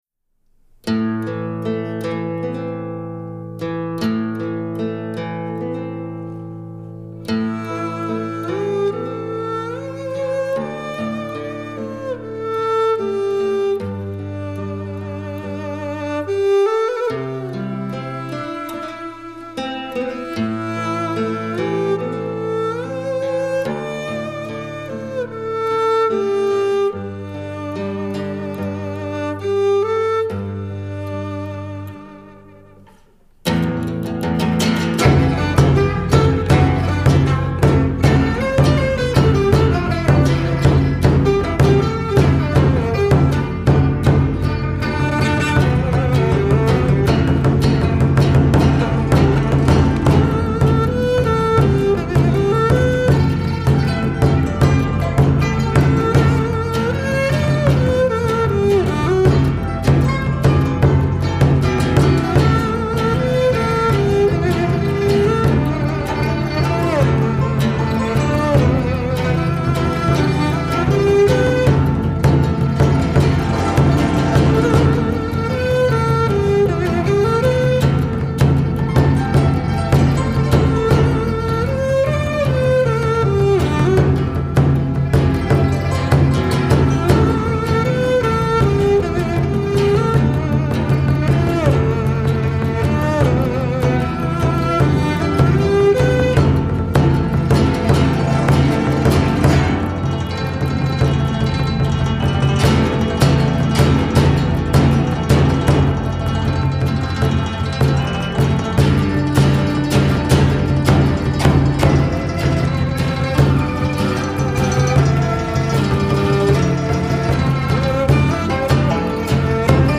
以民族乐器演奏呈现出来。二胡、古筝、琵琶、阮、打击乐交相辉映，相应成趣。
或豪情悲壮、或慷慨激昂、或缠绵悱恻、或意蕴深远，重现一幅幅记忆中的经典画面。
二胡与古筝、琵琶交相辉映，相应成趣: 或抗争，或嬉戏，或诉说.